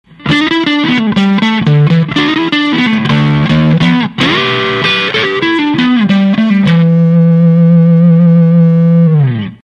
「FUZZ EXPLOSION OFF(80kbMP3)」